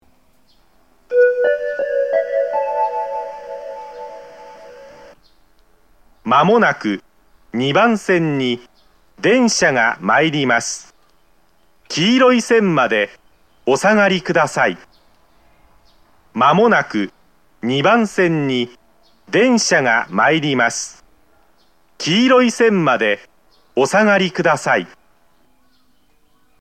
メロディー・自動放送の音が小さい上に、夜間音量が設定されています。
（男性）
接近放送 鳴動開始は到着約3分前です。